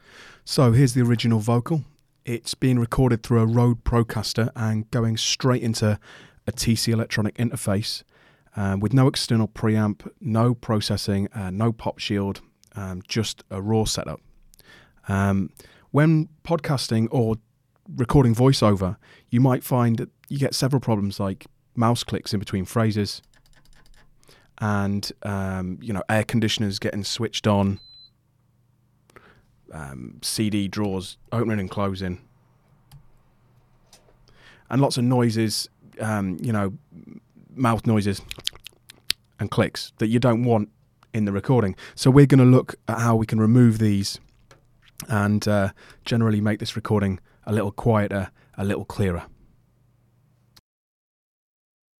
It’s nothing flashy just a few sentences spoken into a Rode Procaster dynamic microphone. This is going straight into a TC electronic firewire interface and isn’t treated in any way.
The untreated recording.
In this case I deliberately included some loud lip smacking sounds towards the end of the passage.